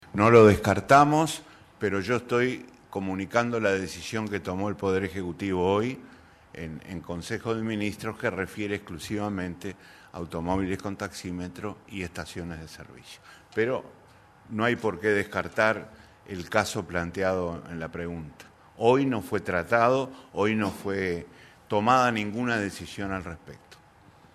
Tras un nuevo Consejo de Ministros, el encargado de Economía, Danilo Astori, comunicó en conferencia de prensa la decisión del Gobierno de eliminar en aproximadamente un mes, el uso de dinero en efectivo en el pago a taxistas y estaciones de servicio.